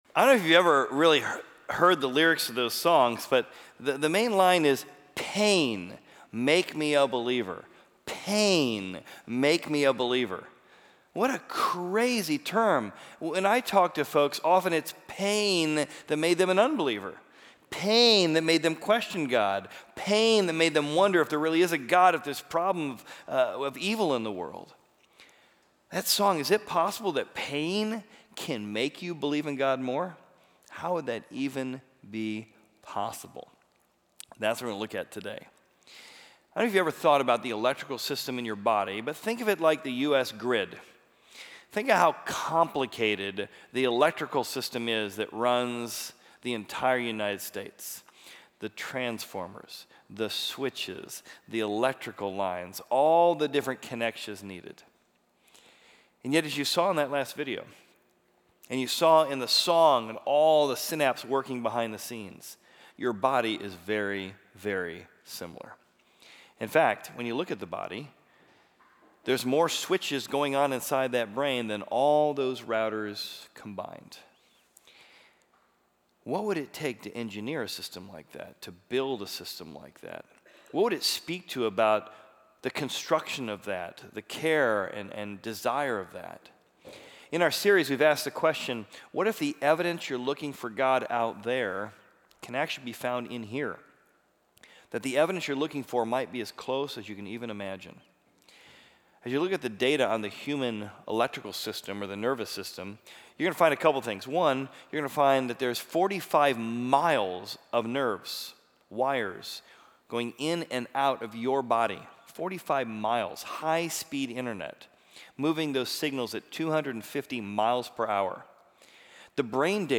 Join in as we aim to bridge science and faith, demonstrating that our eyes, heart, lungs and other body parts point to a purposeful Creator, who we call the Brains of the Operation. The series begins on Feb. 15th at Horizon’s 11 a.m. Exploring Service.